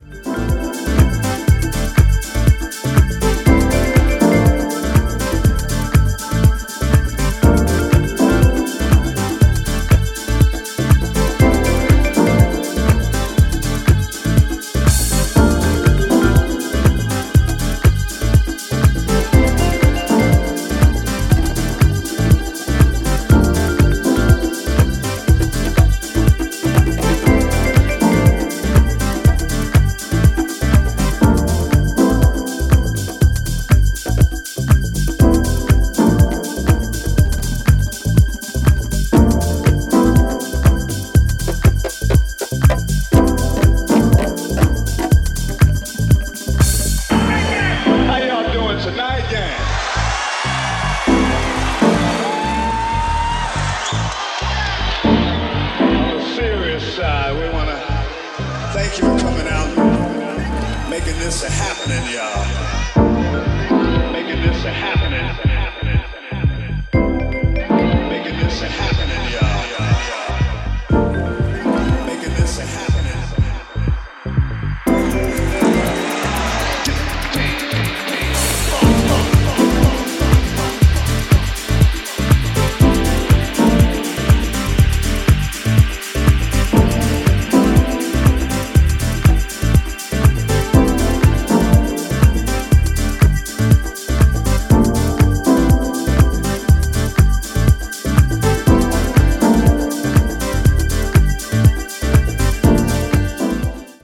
> HOUSE・TECHNO
ジャンル(スタイル) DEEP HOUSE